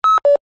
Звуки разговоров по рации
Сигнал CB-радио бип